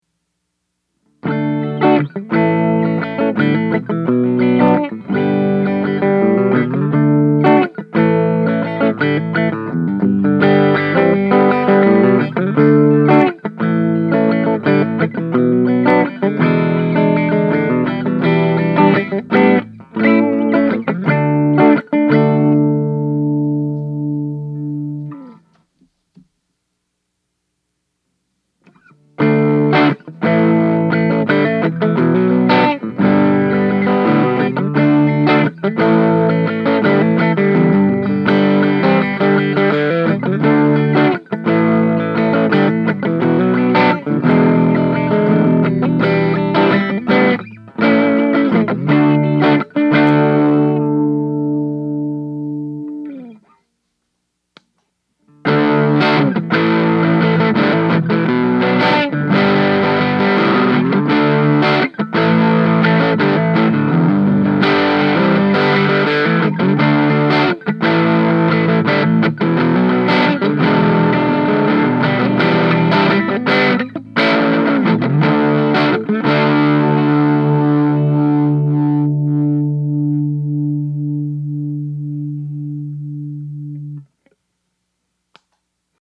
With this next clip, the amp is set clean, with the Master all the way up, the Gain at about 9am.
The first part is clean, bluesy riff. In the second part, I turn the CPC to noon (~20 Watts), and play the same riff. Finally, I engage the boost to demonstrate how over-the-top you can get with this amp.